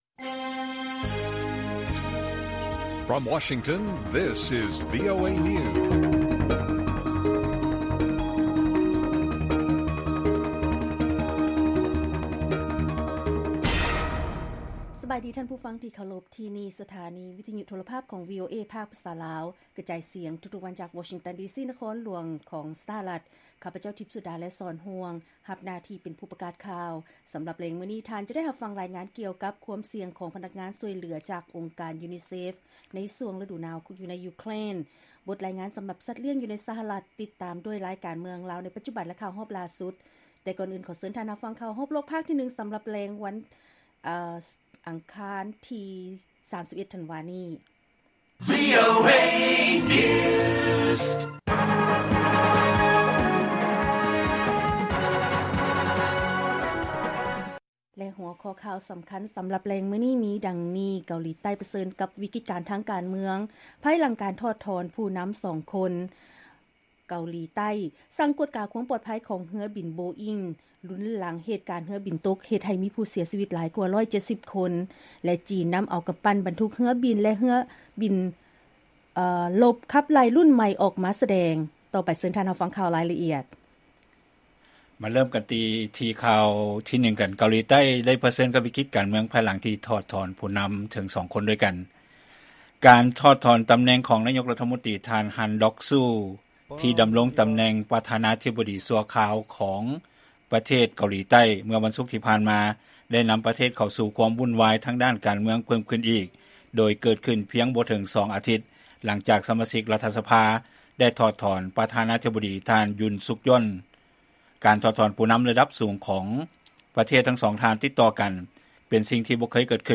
ລາຍການກະຈາຍສຽງຂອງວີໂອເອລາວ: ເກົາຫຼີໃຕ້ ປະເຊີນວິກິດການທາງການເມືອງ ພາຍຫຼັງການຖອດຜູ້ນໍາສອງຄົນ